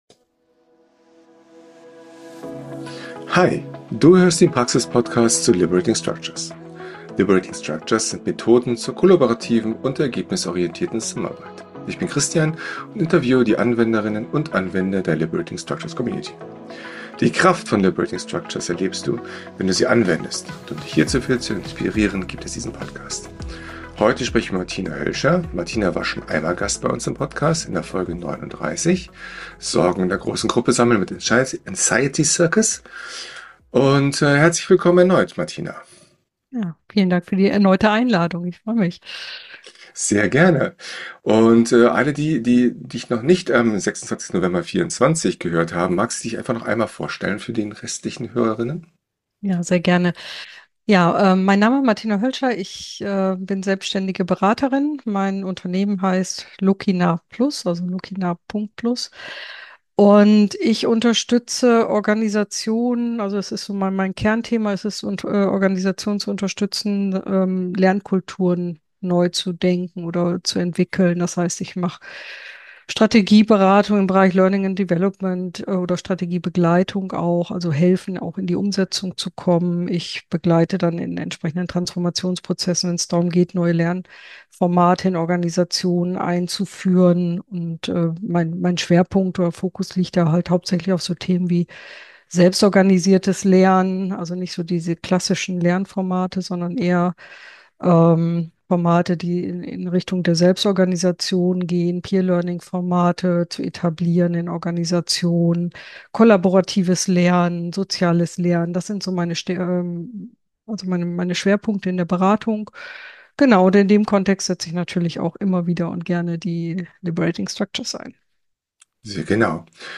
Herzlich willkommen zum Praxispodcast zu Liberating Structures!